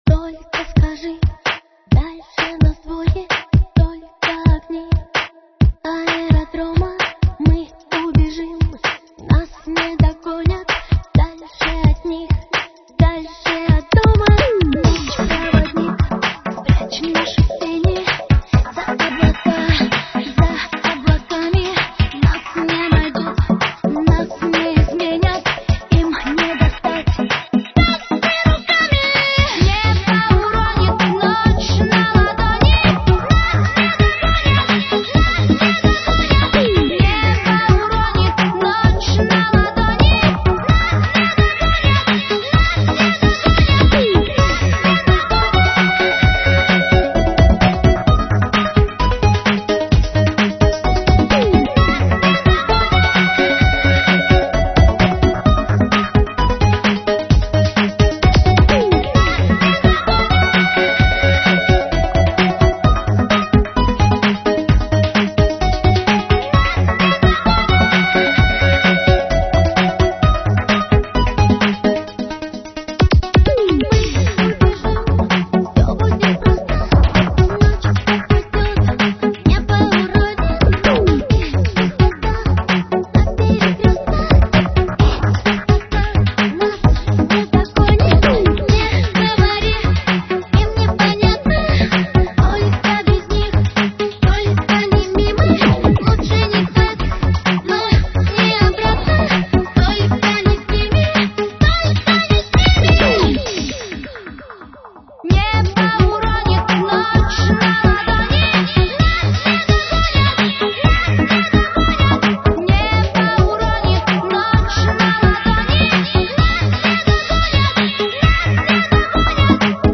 dance/electronic
Techno
IDM
Dancehall